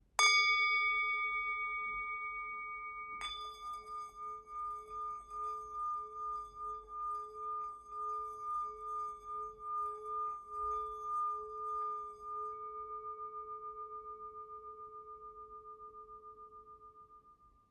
Tibetská mísa Chu malá
Tepaná tibetská mísa Chu o hmotnosti 386 g a malá palička s kůží
tibetska_misa_m60.mp3